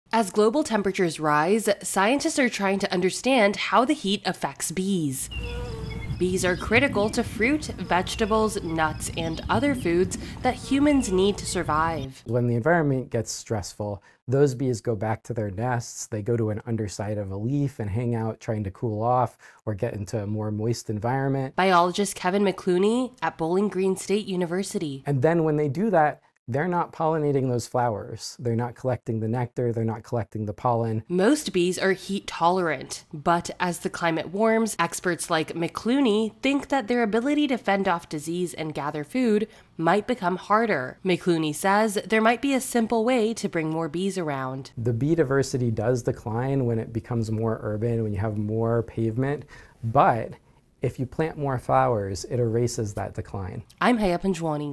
reports on how the heat is affecting bees.